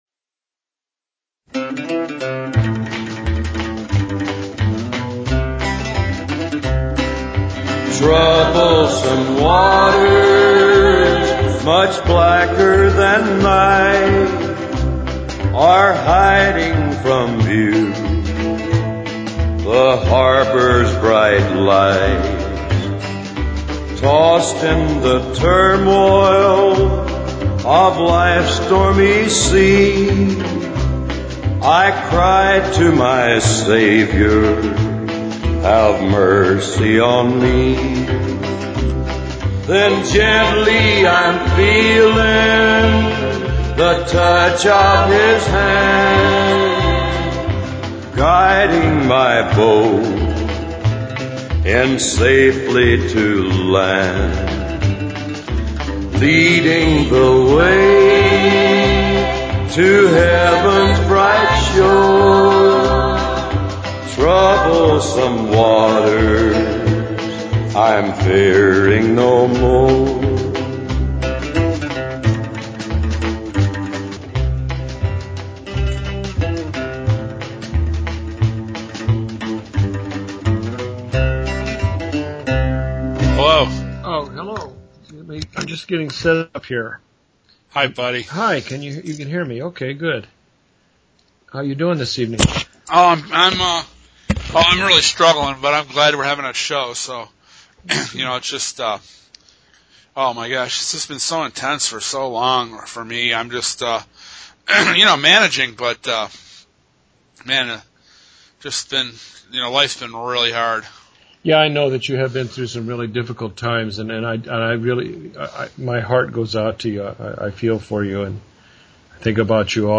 Watchmen Roundtable